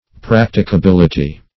Practicability \Prac"ti*ca*bil"i*ty\, n.